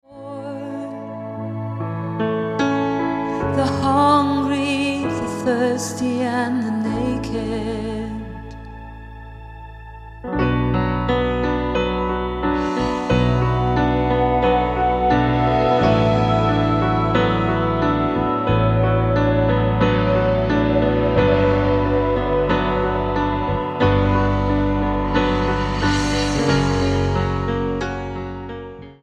STYLE: Celtic